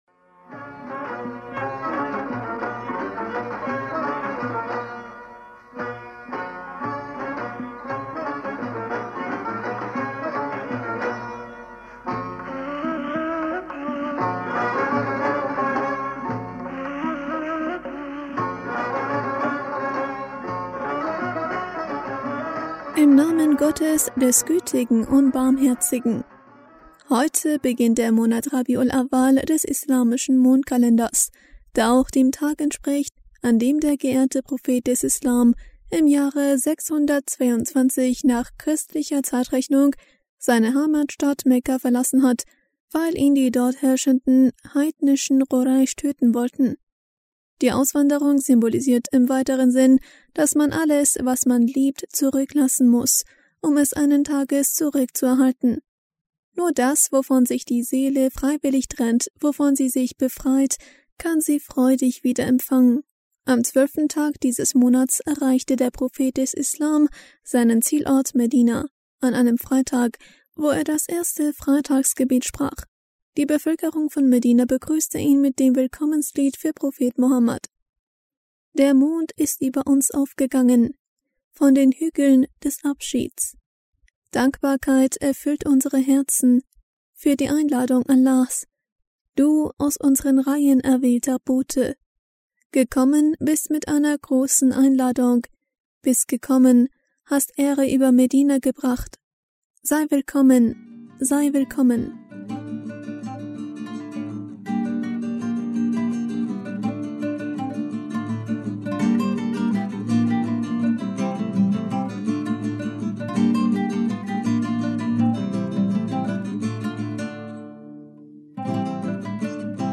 Hörerpostsendung am 18. Oktober 2020